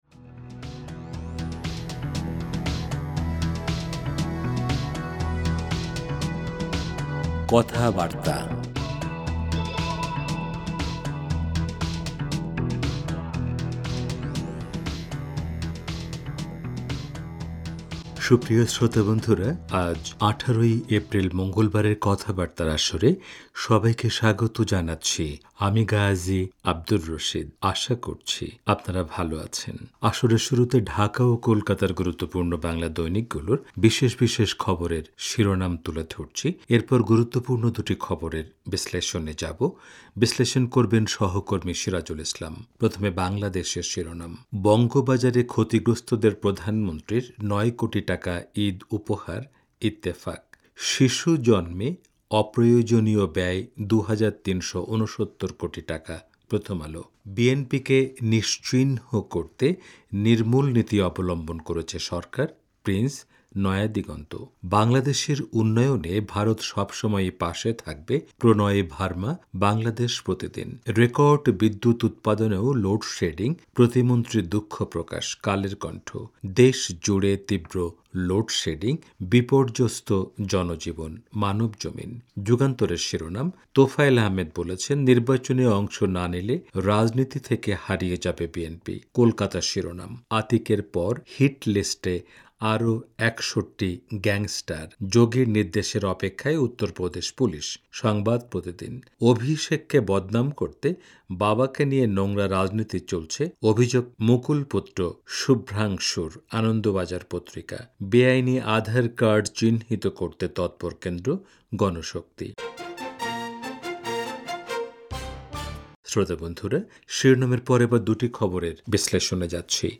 রেডিও অনুষ্ঠানমালা